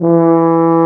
BRS TUBA F0L.wav